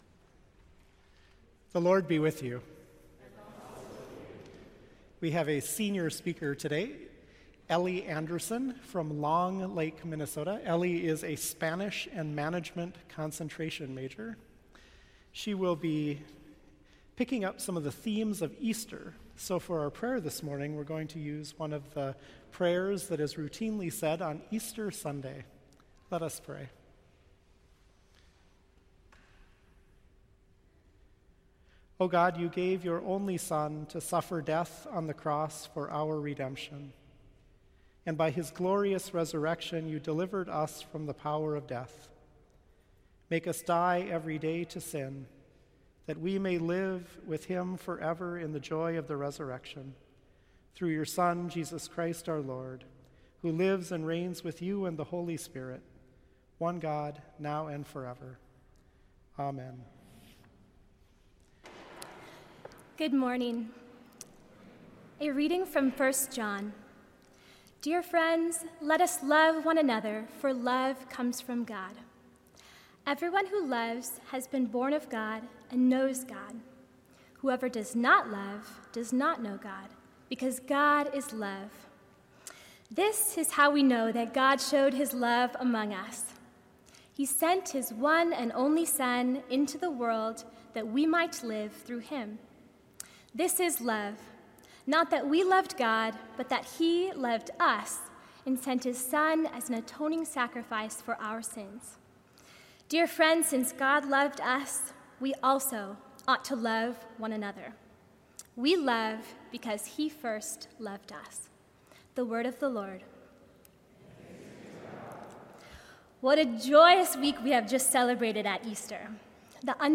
Chapel Service
Boe Memorial Chapel